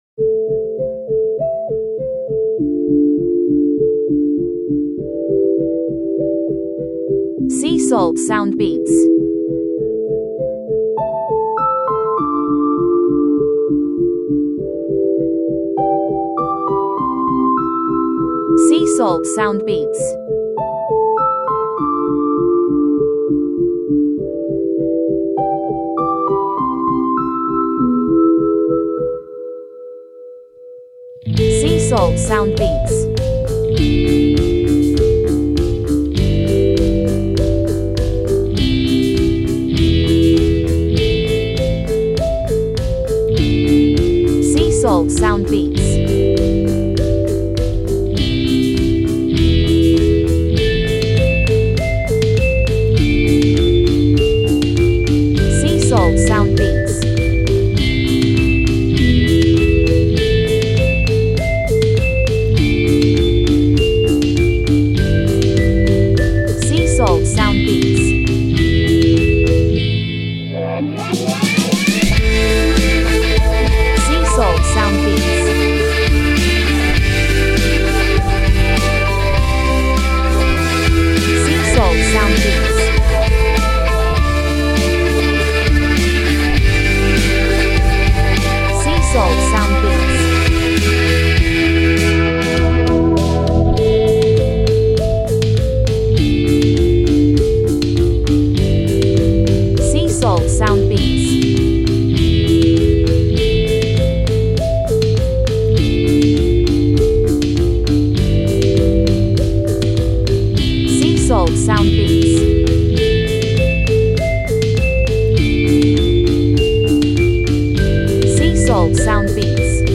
Rock Веселый 100 BPM